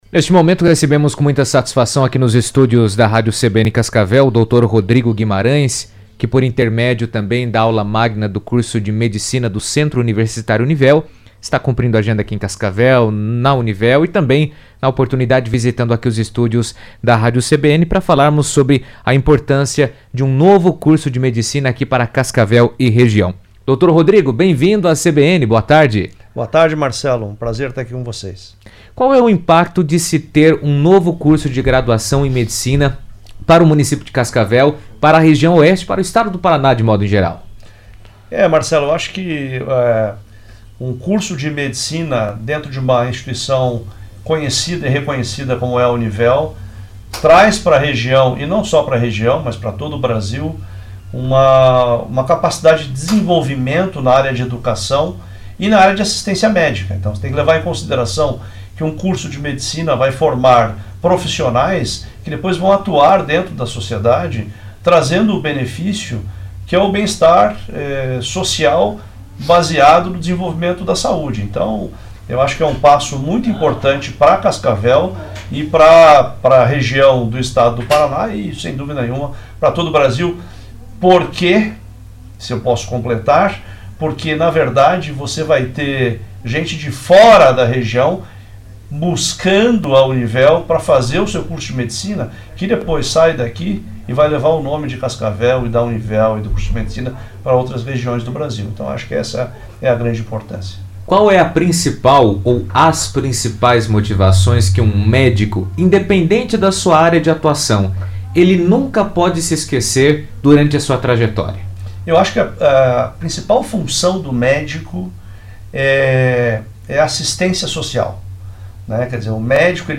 Durante entrevista na CBN, falou sobre importantes avanços médicos e a aplicação prática das novas tecnologias na medicina.